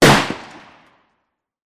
gunshot_rifle_exterior_003